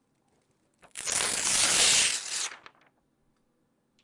许多东西挤在一起V1 " 撕纸
描述：撕纸
Tag: 翻录 翻录 拟音